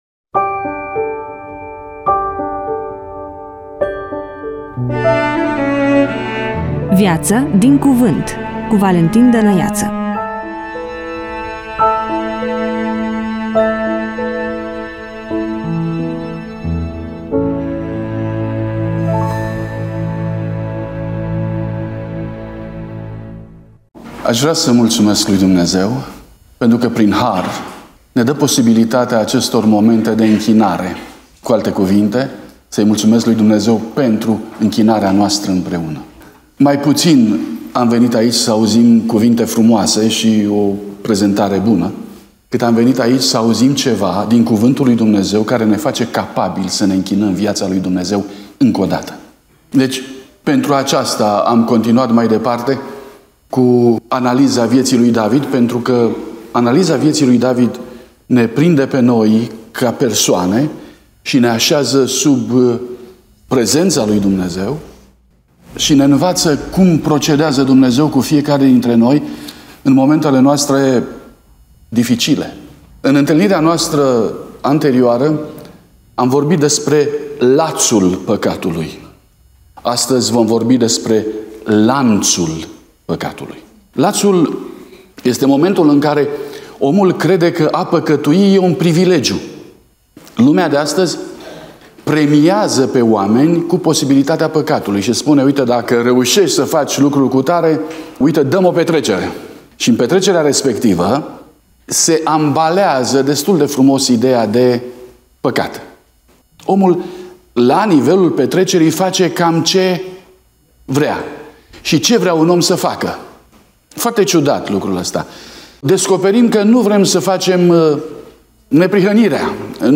Index of /emisiuni/2024/03 Martie/predica de seara/